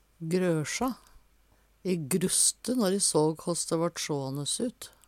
grøsja - Numedalsmål (en-US)
DIALEKTORD PÅ NORMERT NORSK grøsja grøsse Infinitiv Presens Preteritum Perfektum grøsja grøsj grusste grusst Eksempel på bruk E grusste når e såg håss dæ vart sjåanes ut.